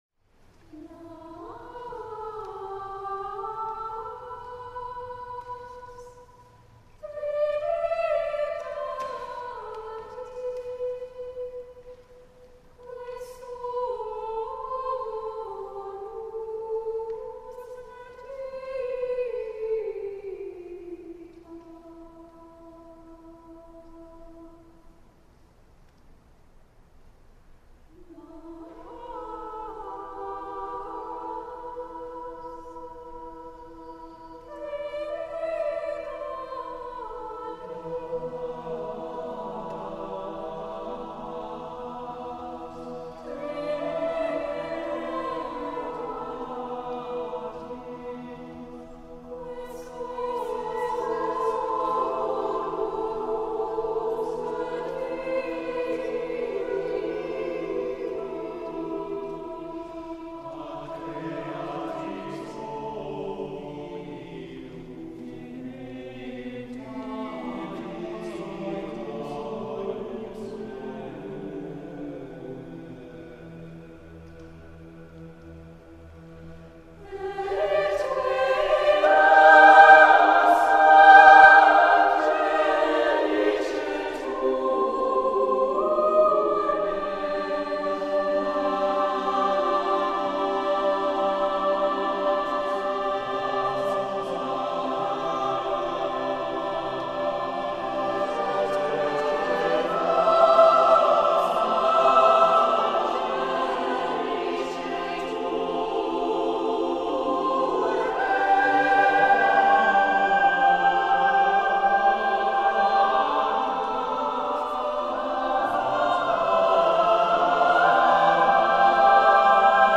Voicing: SSATBB a cappella